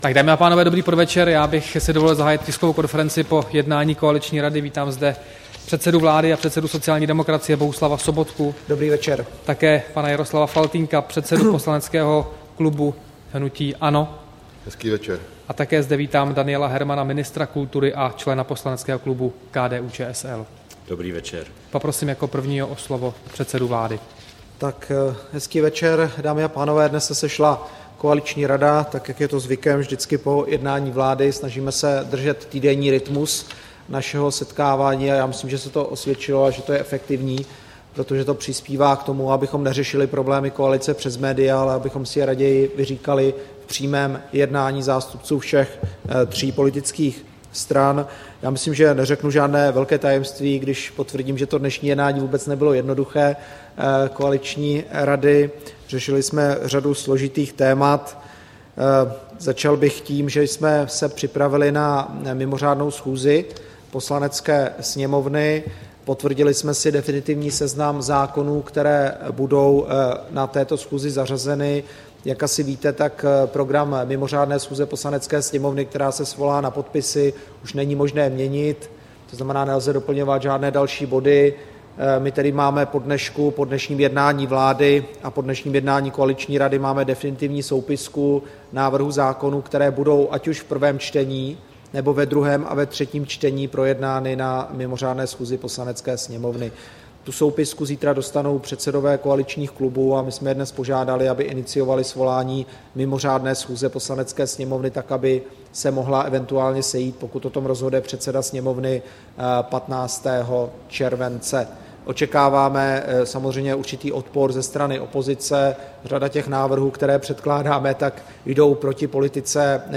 Tisková konference po jednání koaliční rady, 2. července 2014